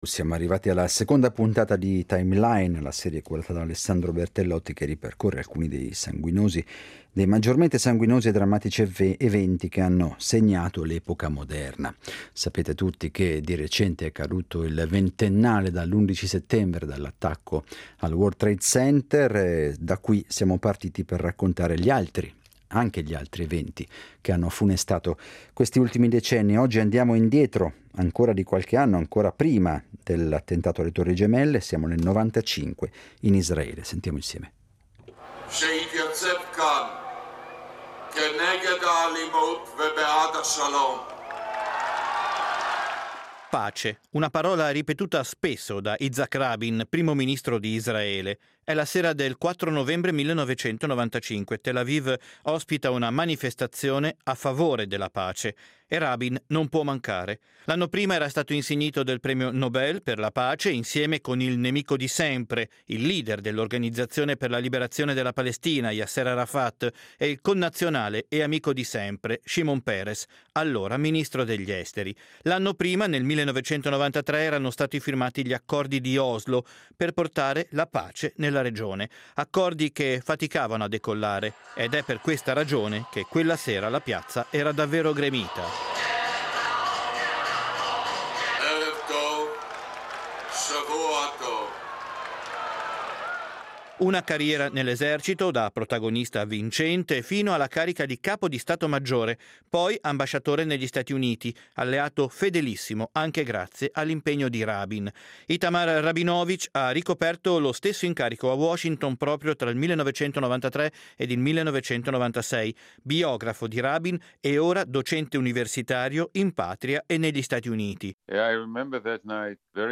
La selezione dei fatti e dei personaggi è stata fatta sulla base della forza dirompente che alcuni eventi hanno avuto nell'orientare poi la vita di una società o di un paese intero: dalla morte di Rabin al rapimento Moro seguiremo per 3 settimane, ogni giorno alle 18:10, quest'antologia di fatti con voci: sono le voci di chi ha saputo documentare o ha assistito a questi cambiamenti epocali.